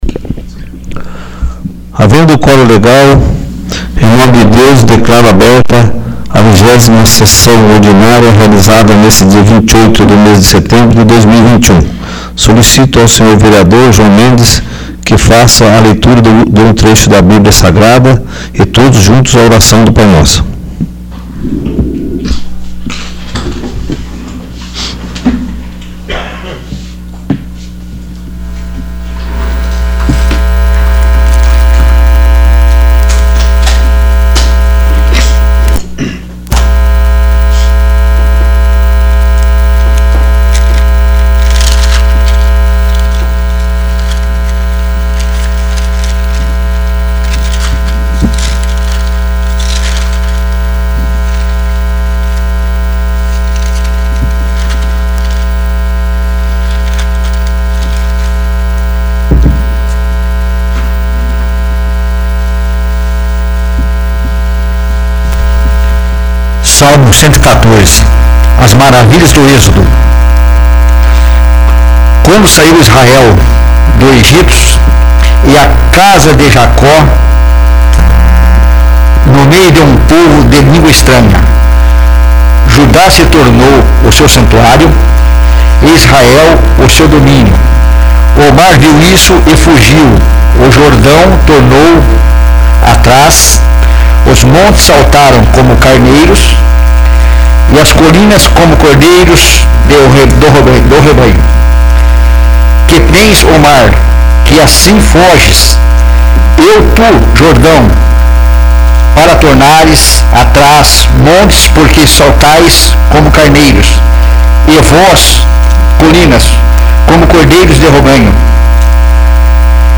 27º. Sessão Ordinária 28/09/2021
27º. Sessão Ordinária